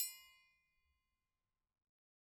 Triangle6-Hit_v2_rr1_Sum.wav